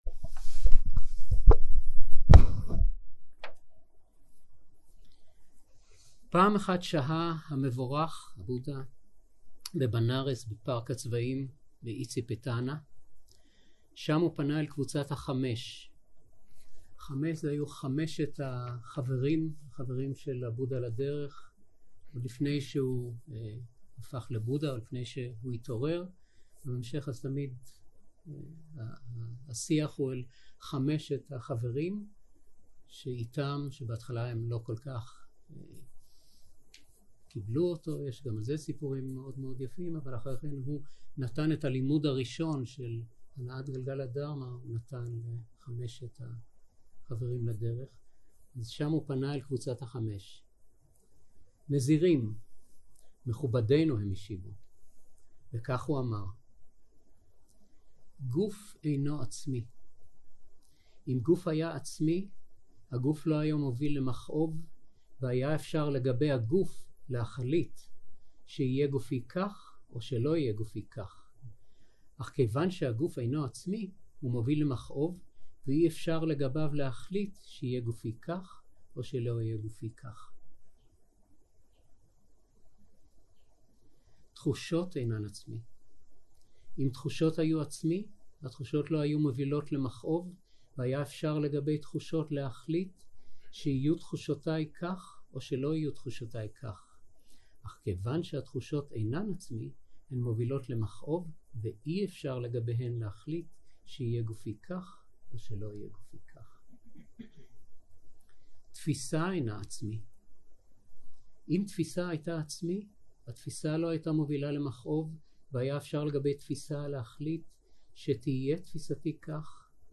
יום 6 - צהרים - הנחיות למדיטציה וצ'אנטינג - הקלטה 14
Dharma type: Guided meditation שפת ההקלטה